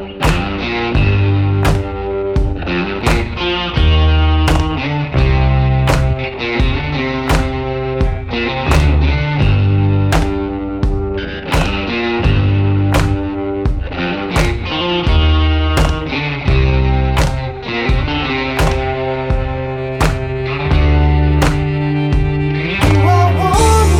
no Backing Vocals Indie / Alternative 4:30 Buy £1.50